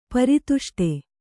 ♪ pari tuṣṭe